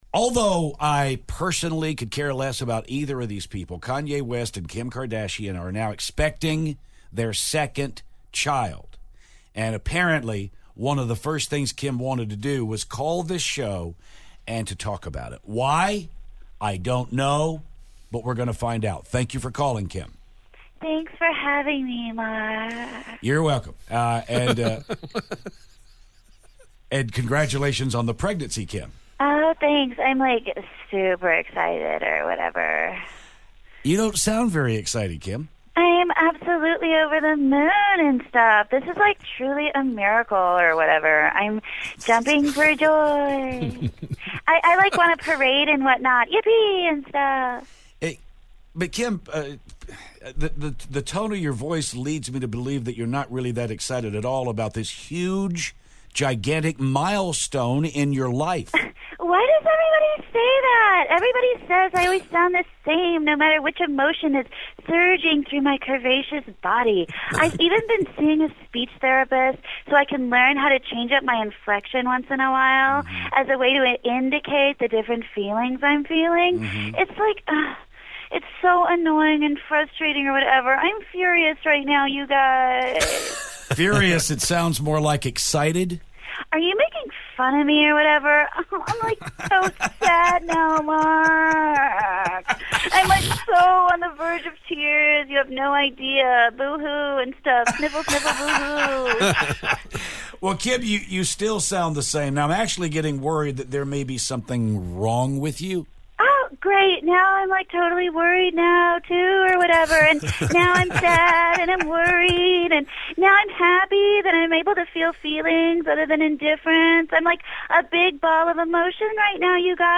Kim Kardashian calls the show to talk about her pregnancy.